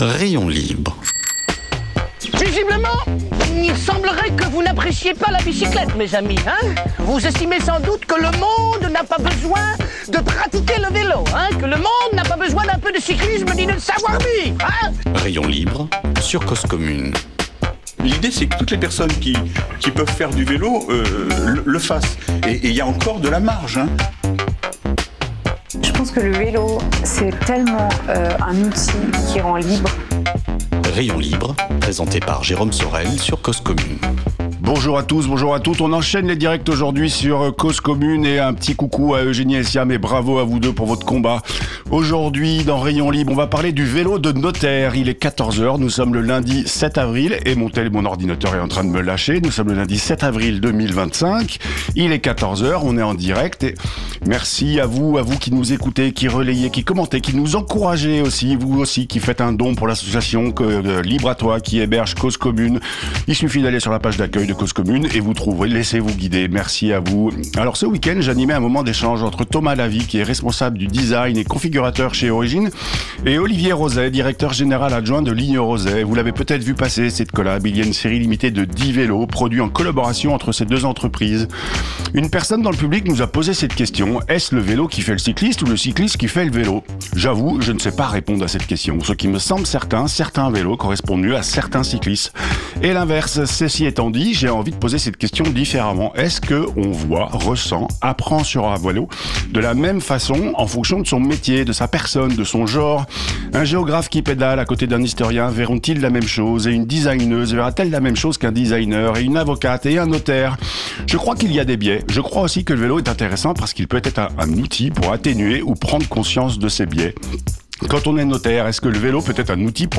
En plateau